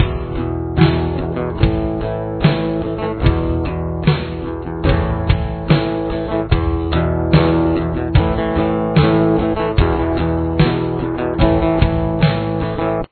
Guitar 1